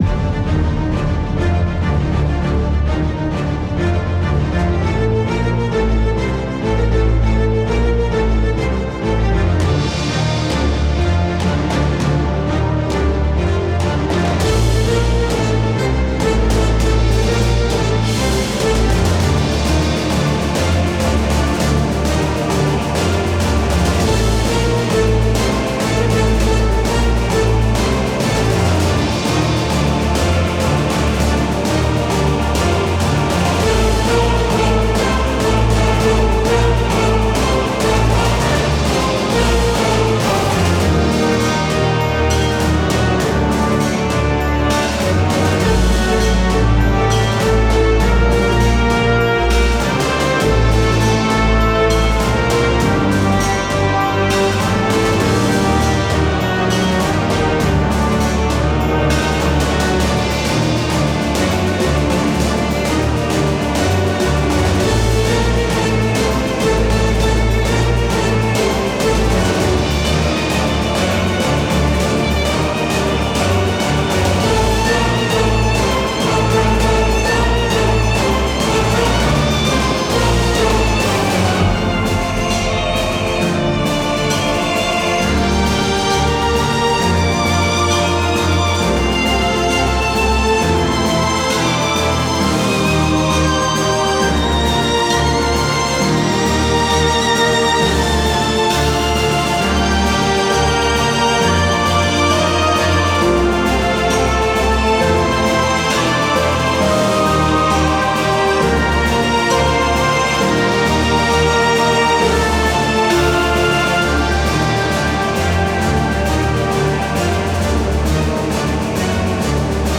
I made a fanmade battle theme for AO Dragons!
I got inspired you could say ^-^ I… might’ve gone a bit overboard and made it sound a bit like a final boss theme